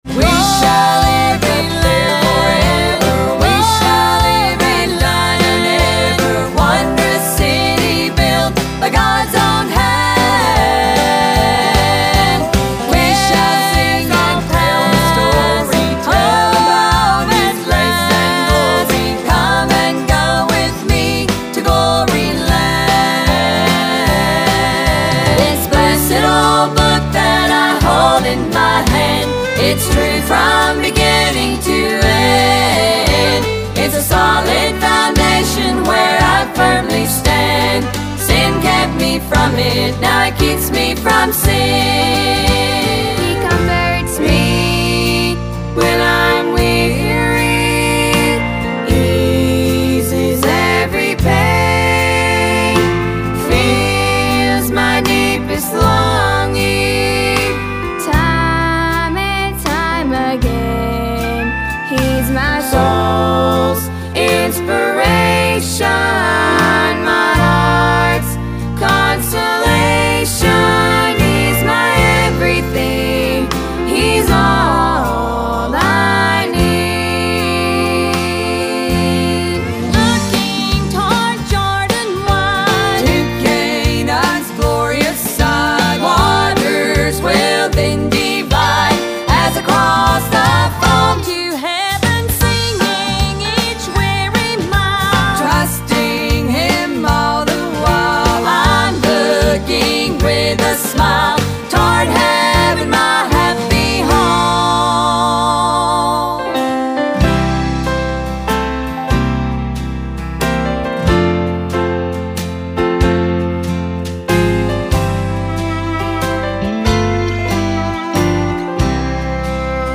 Celebrating 50 years of singing Southern Gospel Music!